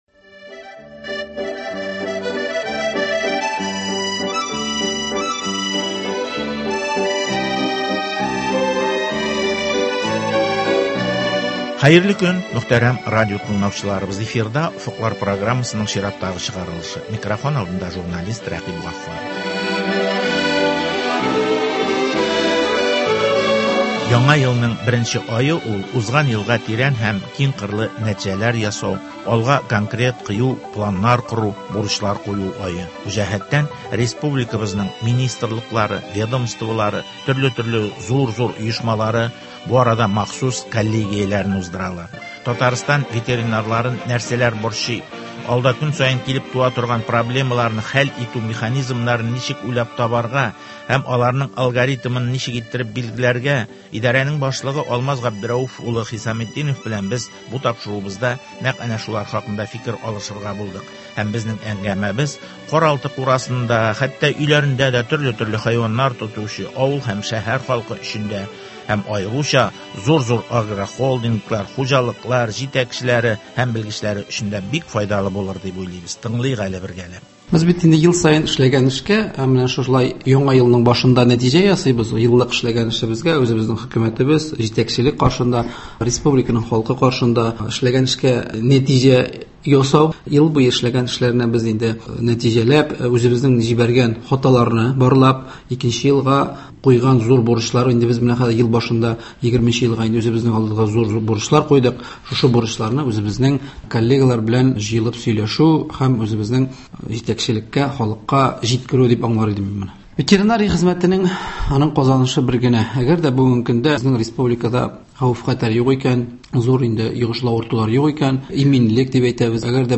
Татарстан Министрлар Кабинетының баш ветеринария идарәсе җитәкчесе Алмаз Хисаметдинов белән алар алдында торган проблемалар, кыенлыклар хакында әңгәмә.